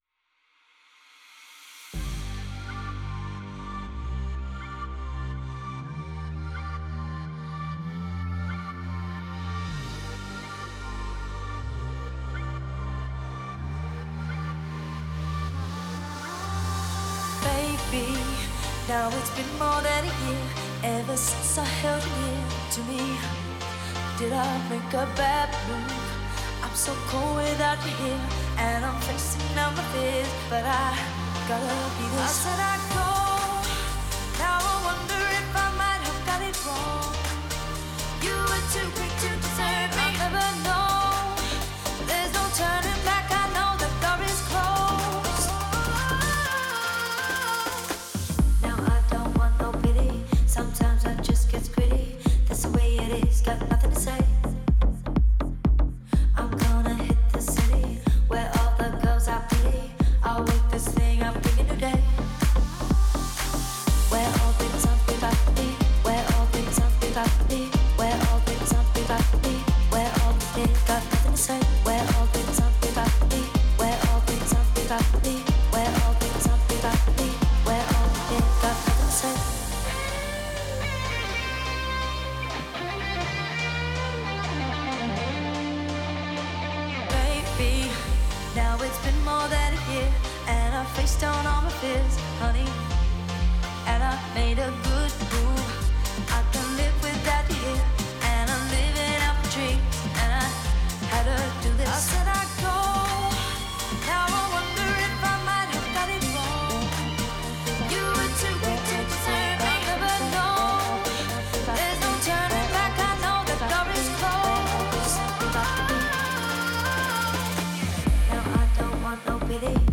это трек в жанре поп с элементами электронной музыки
нежный вокал
динамичными электронными битами, создавая эффектный контраст